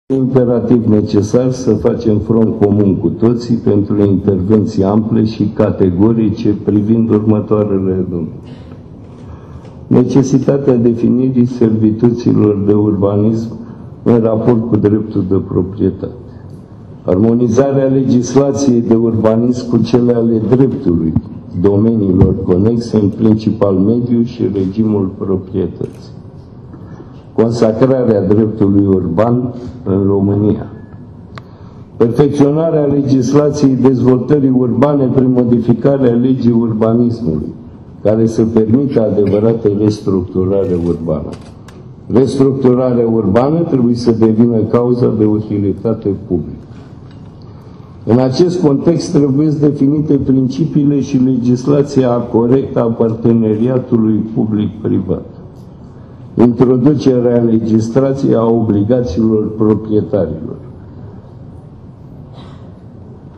Declarațiile au fost făcute la Forumul Național al Orașelor “Think City”.